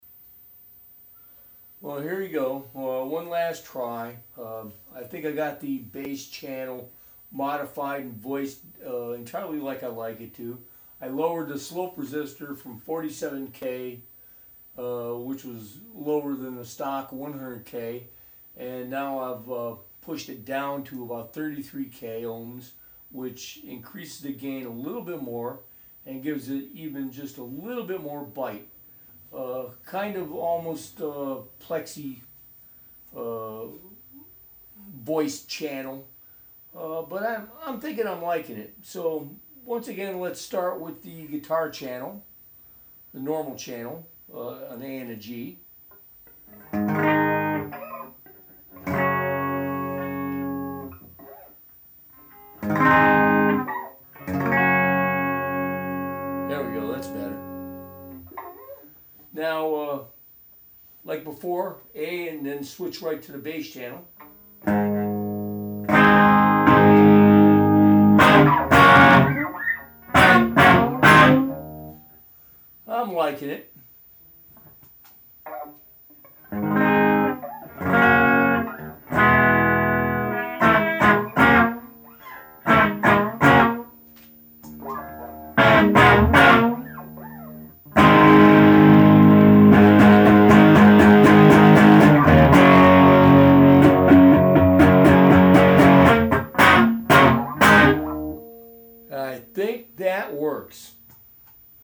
This should raise the gain a bit more and give the sound even more bite to it.
Finally, this is with the slope resistor changed to 33K and of course the hi-cut cap removed from the circuit.
Bass and Guitar Channel 33K Ohm bass slope A/B